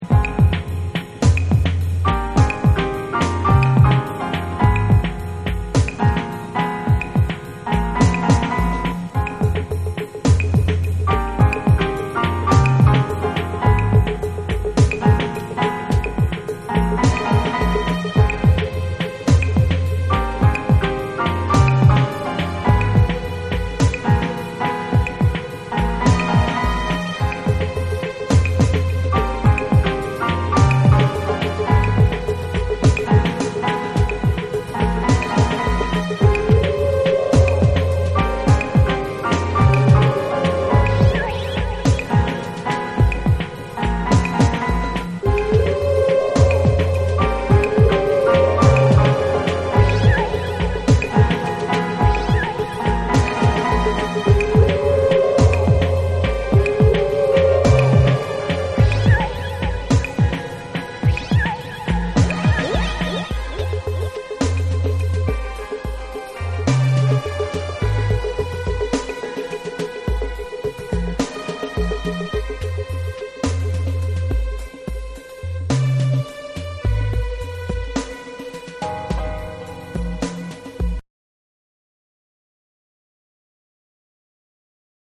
TECHNO & HOUSE / BREAKBEATS